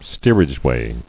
[steer·age·way]